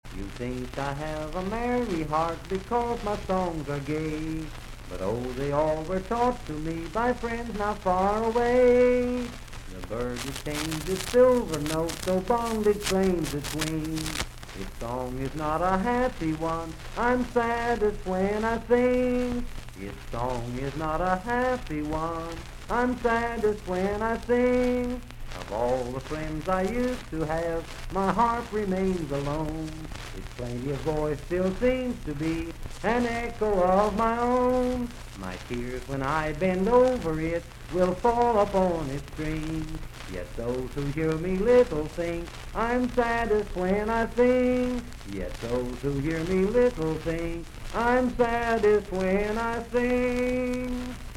Unaccompanied vocal music
Verse-refrain 2(10w/R).
Voice (sung)
Parkersburg (W. Va.), Wood County (W. Va.)